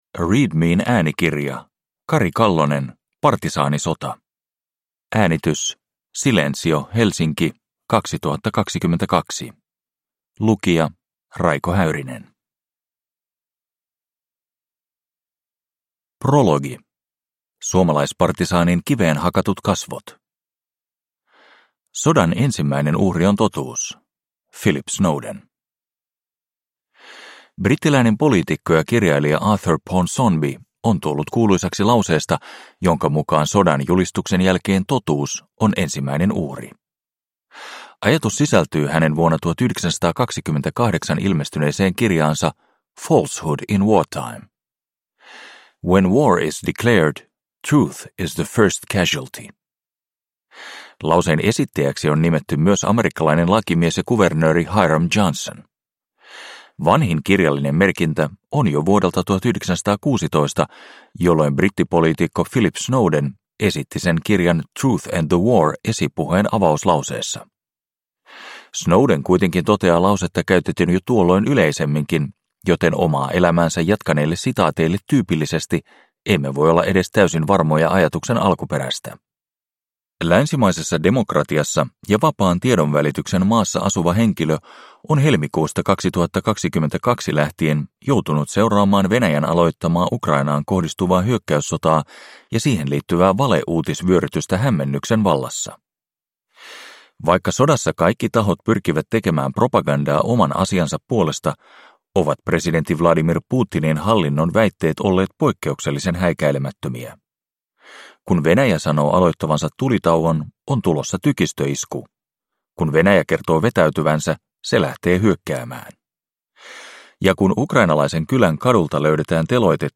Partisaanisota – Ljudbok